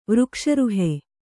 ♪ vřkṣa ruhe